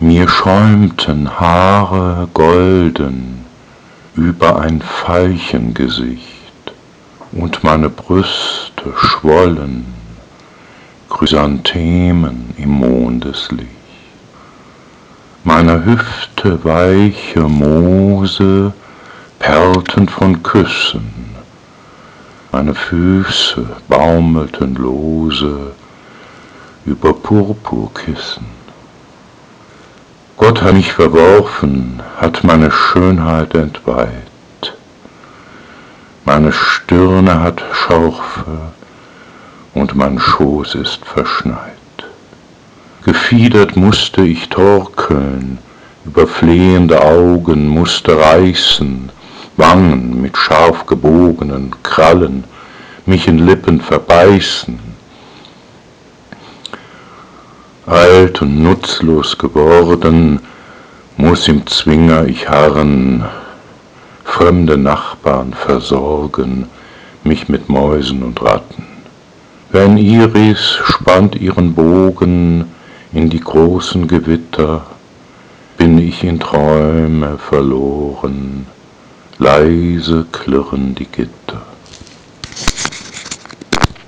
Rezitation: